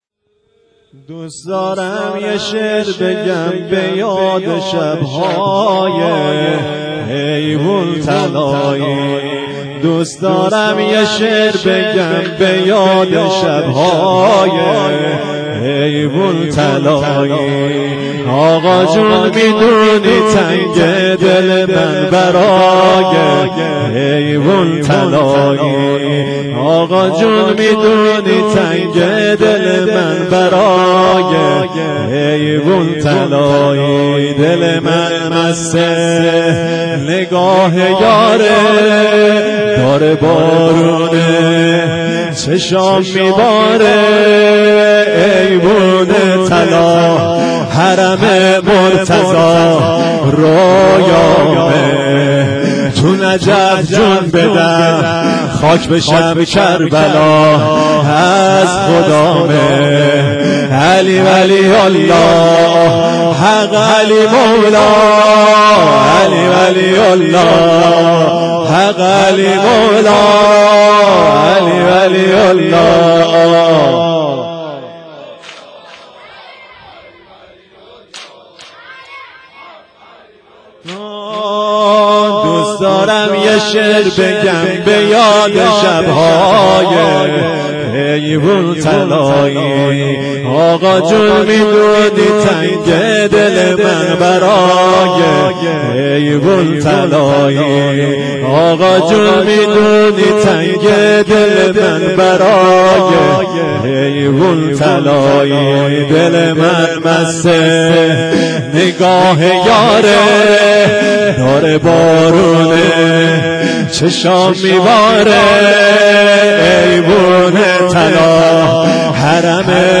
شب 21 رمضان 92